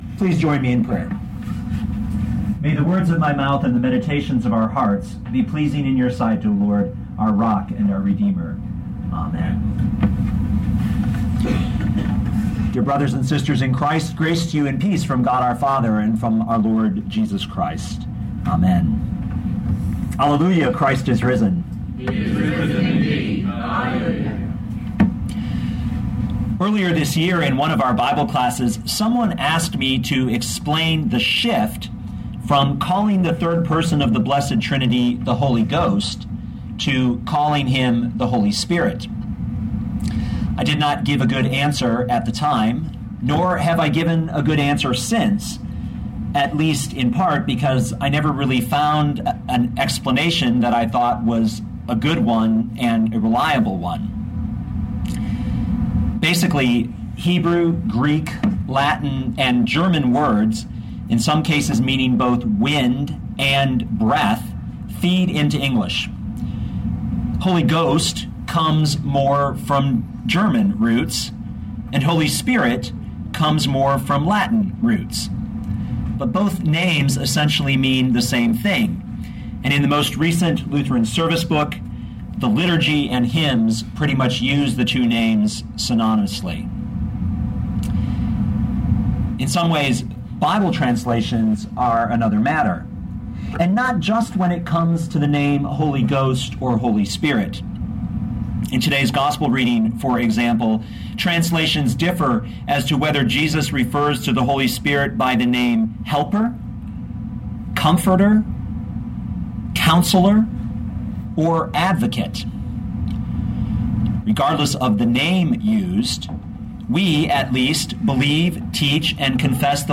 16:4b-15 Listen to the sermon with the player below, or, download the audio.